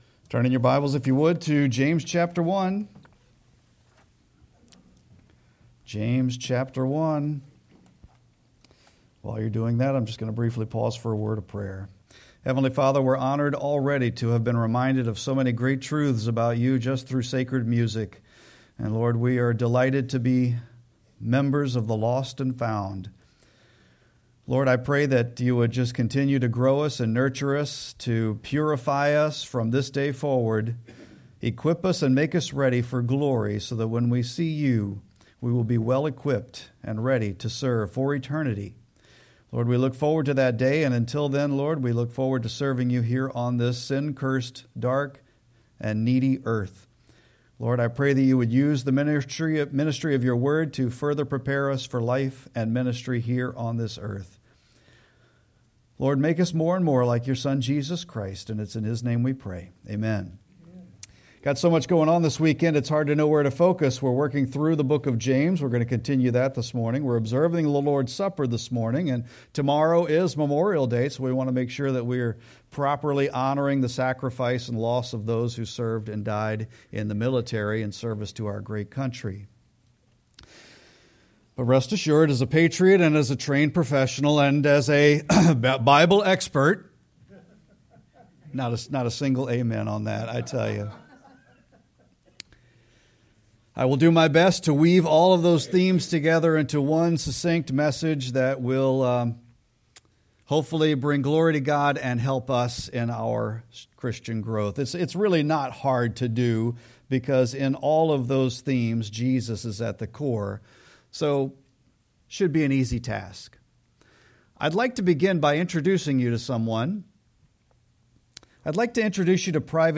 Sermon-5-25-25.mp3